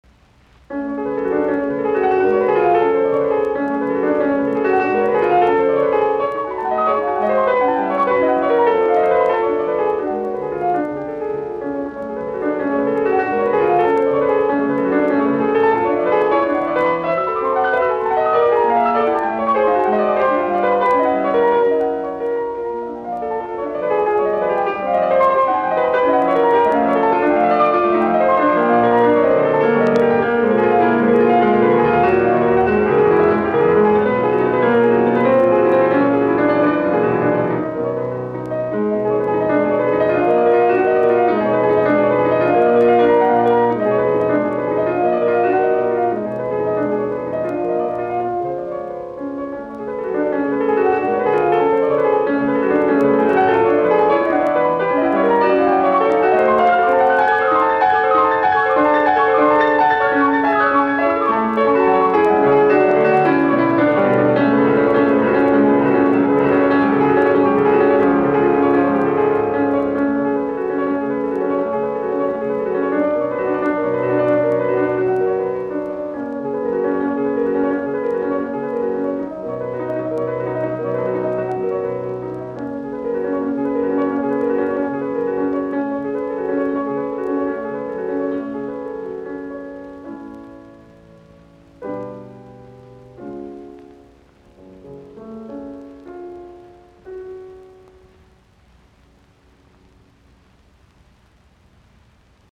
piano
No. 8 in f-sharp minor, Molto agitato